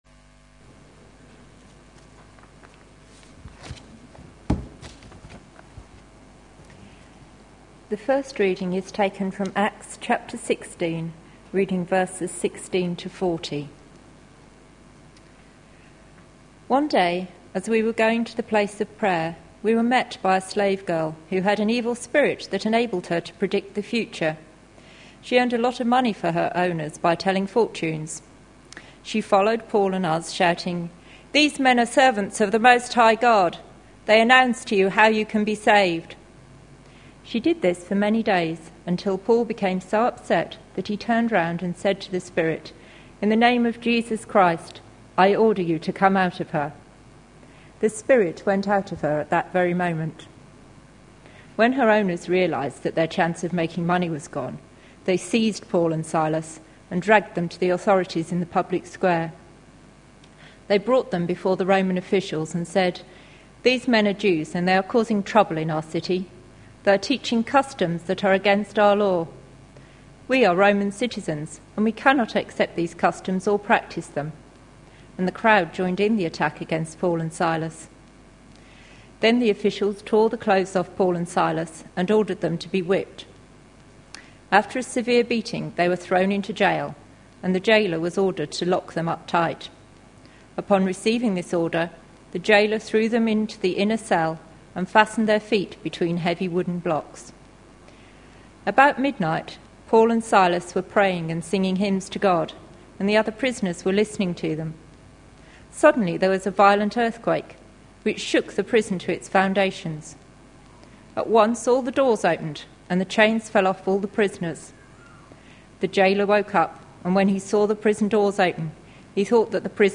A sermon preached on 29th May, 2011, as part of our God at Work in..... series.